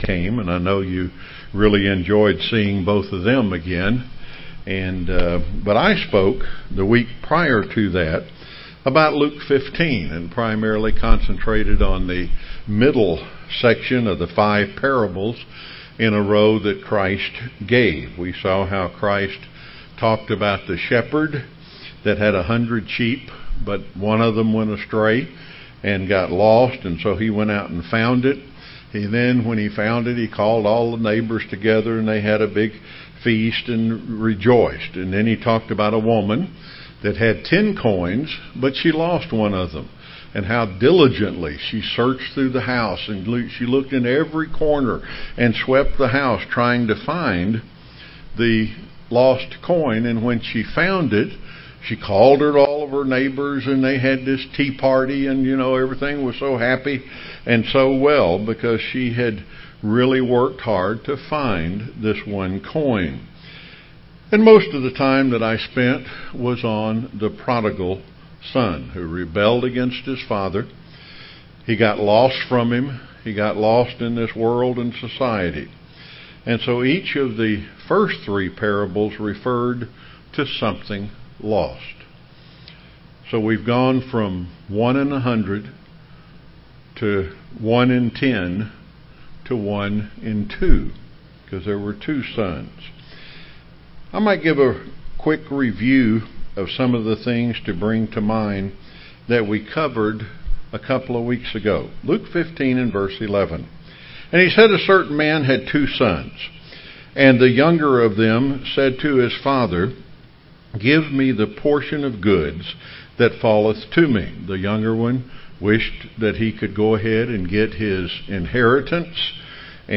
Given in Rome, GA
UCG Sermon Studying the bible?